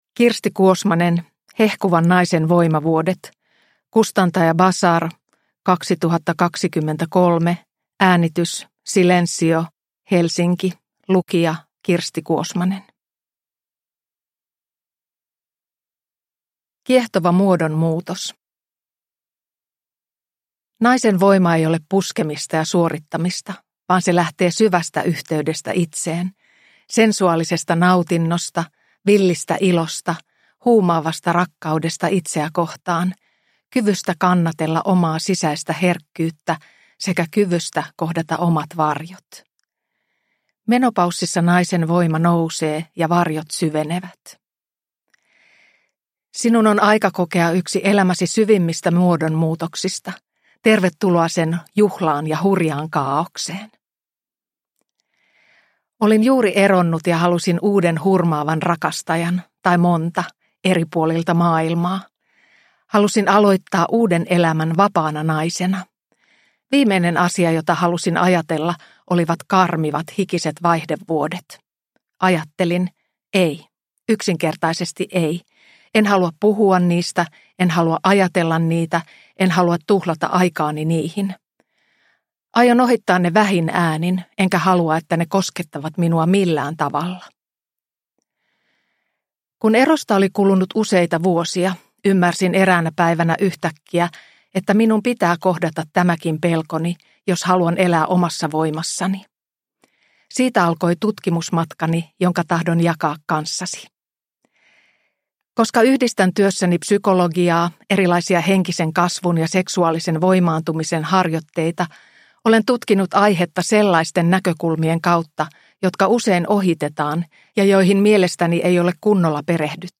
Hehkuvan naisen voimavuodet – Ljudbok – Laddas ner